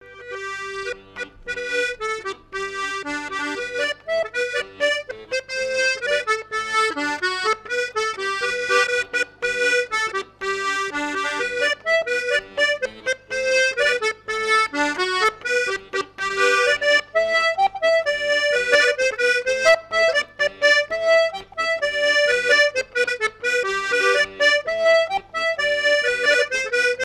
danse : polka
Fête de l'accordéon
Pièce musicale inédite